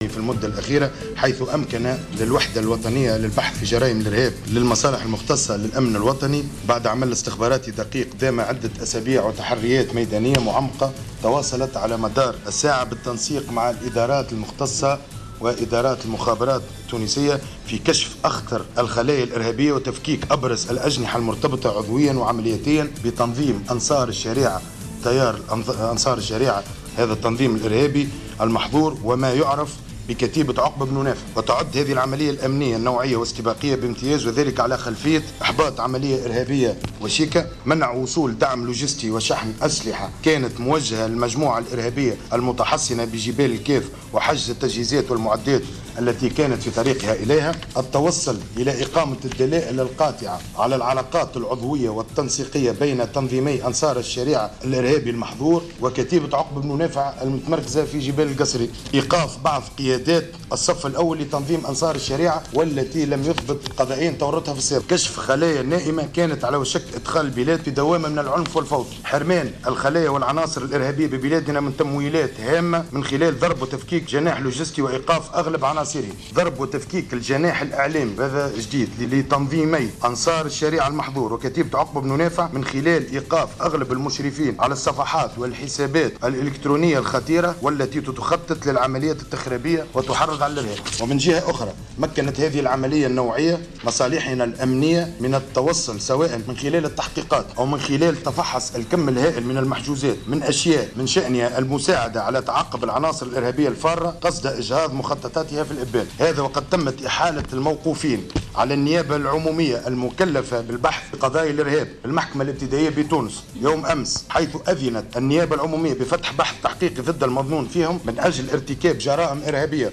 نشرة أخبار منتصف الليل ليوم الأربعاء 15-10-14